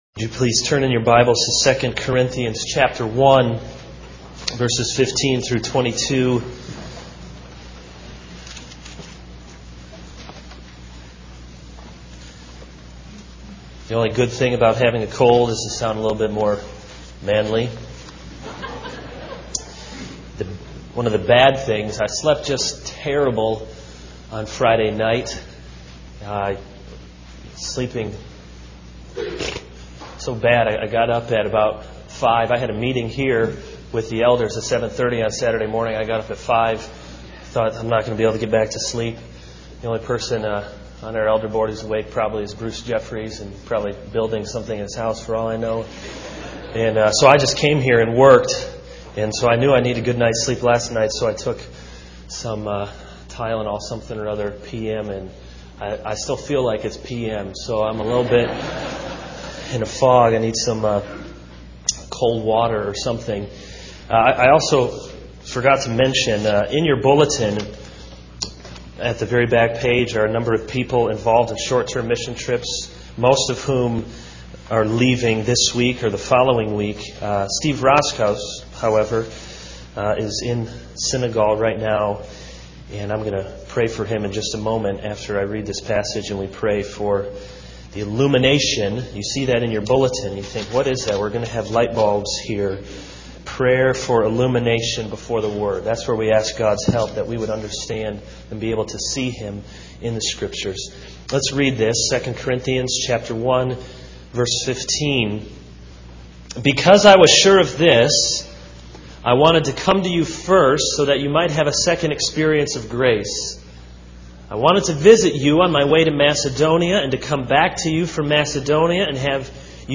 This is a sermon on 2 Corinthians 1:15-22.